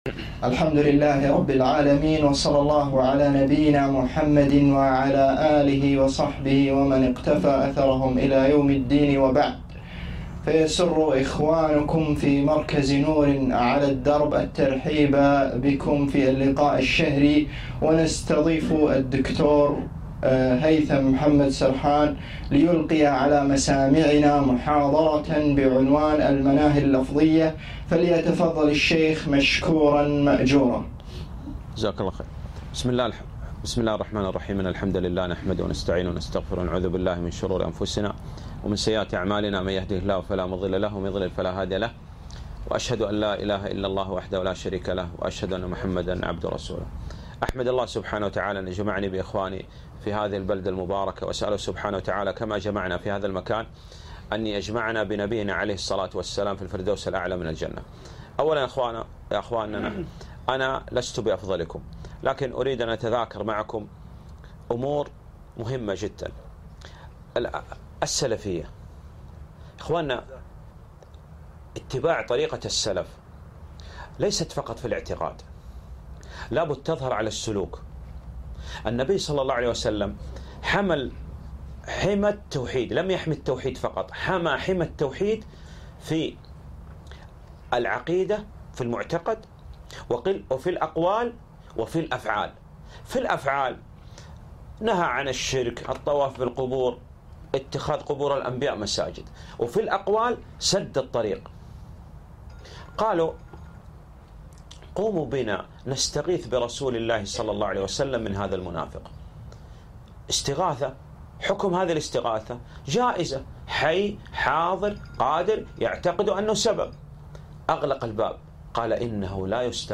محاضرة - المناهي اللفظية